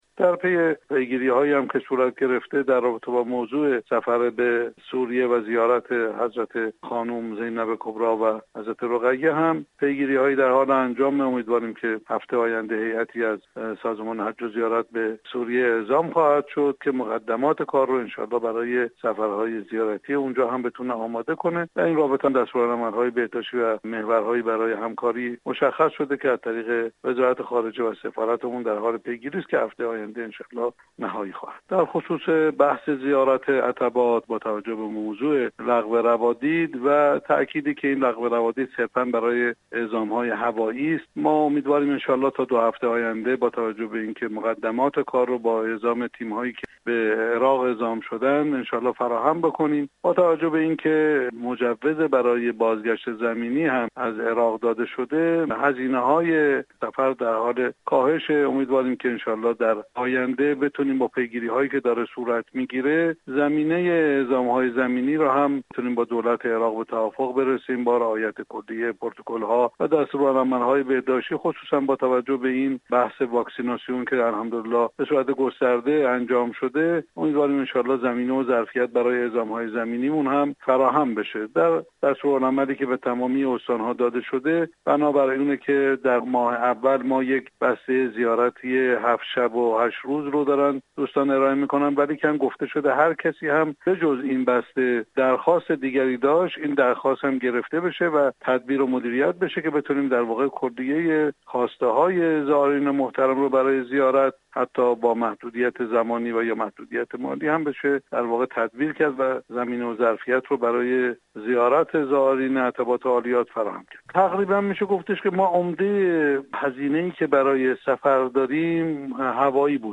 به گزارش رادیو زیارت ، علی رضا رشیدیان رییس سازمان حج و زیارت در گفتگو با این رادیو اظهار داشت : به دنبال پیگیری های صورت گرفته برای راه اندازی سفرهای زیارتی سوریه و زیارت حرم های مطهر حضرت زینب (س) و حضرت رقیه (س) ،طی این هفته هیاتی از سازمان حج و زیارت به سوریه اعزام می شود تا مقدمات سفرهای زیارتی زائران دلداده و مشتاق زیارت را به این کشور فراهم کند.